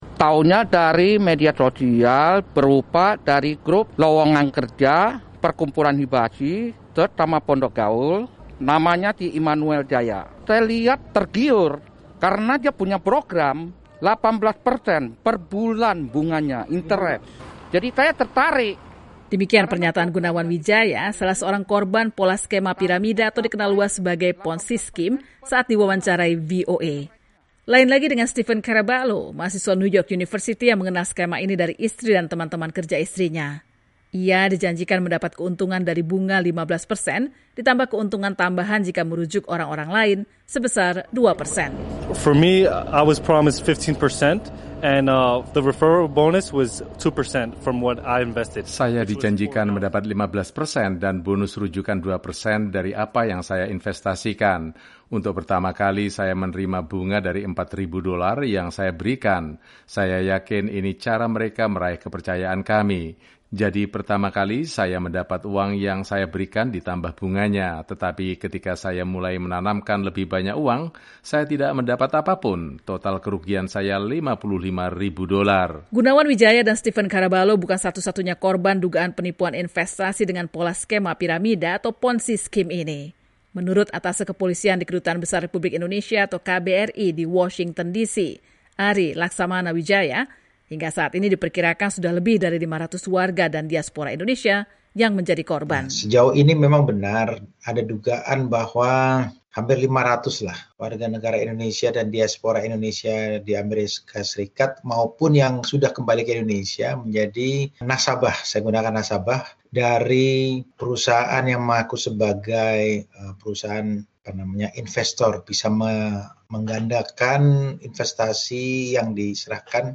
melaporkan dari Washington DC.